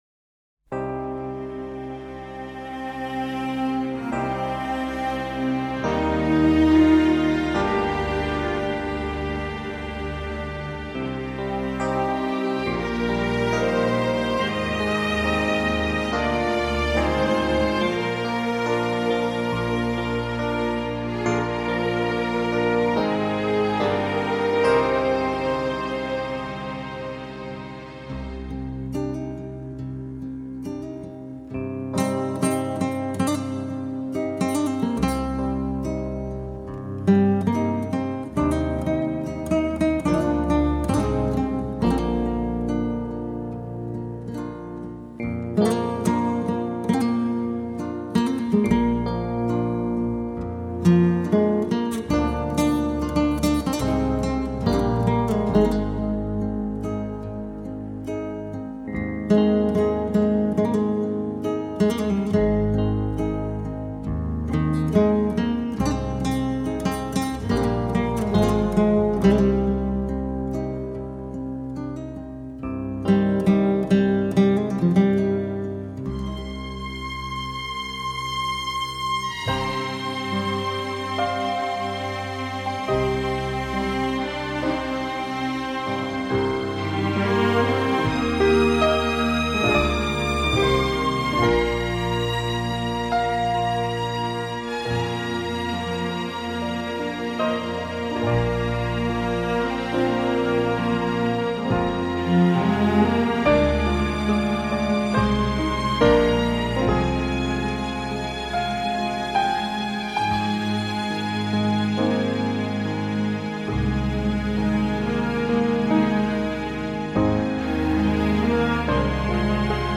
ژانر: بی کلام
توضیحات: اهنگ با سوت معروف